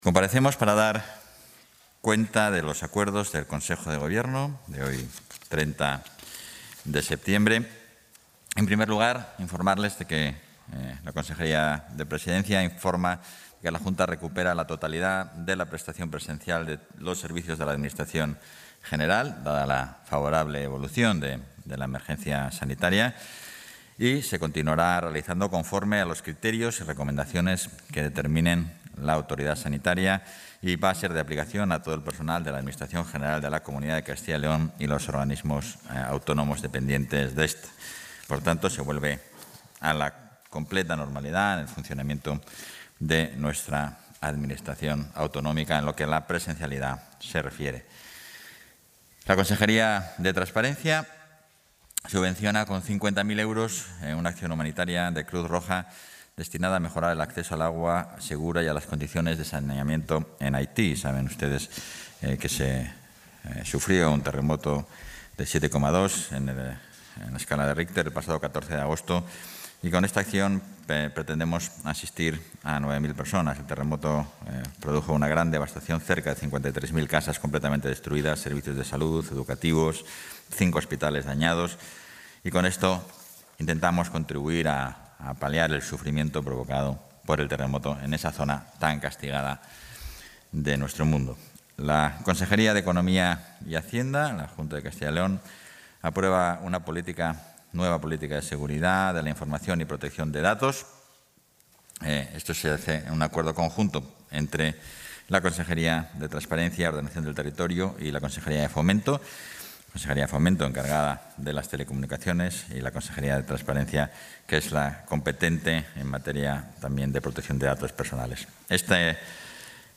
Intervención portavoz.
Consejo de Gobierno del 30 de septiembre de 2021.